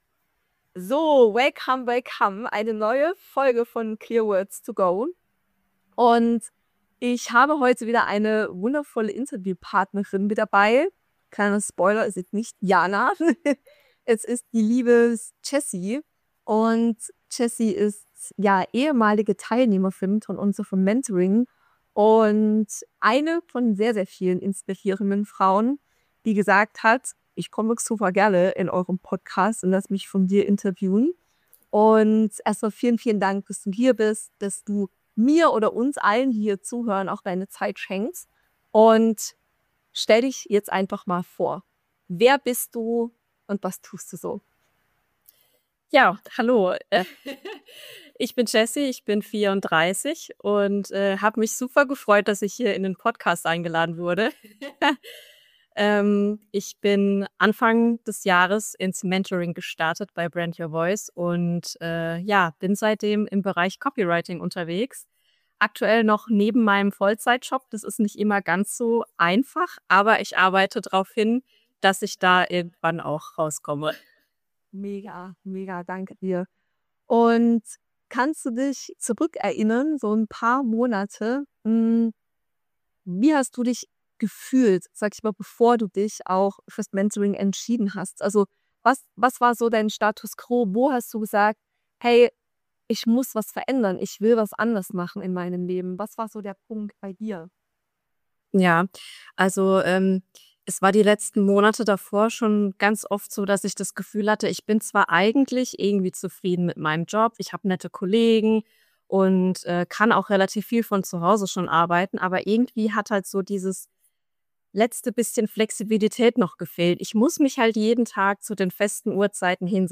Klick dich rein und lausche einem emotionalen Gespräch über Entscheidungen, erste Kunden und das krasseste Gefühl der Welt: sich selbst zu beweisen, dass da noch viiiel mehr geht!